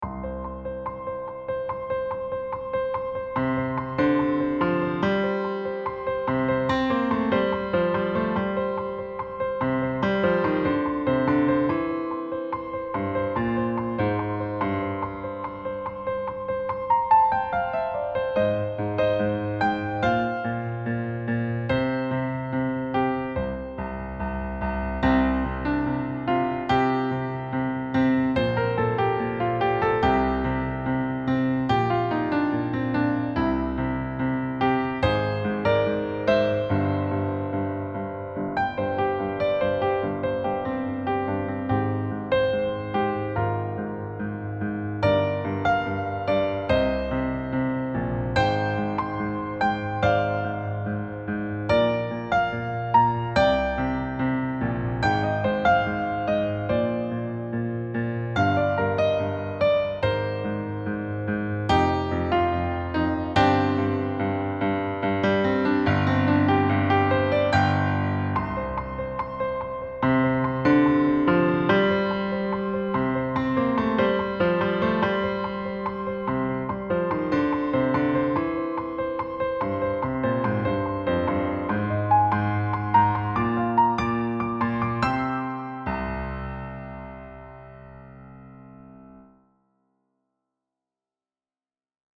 • Key: C Mixolydian Mode (with occasional accidentals)
• Time signature: 4/4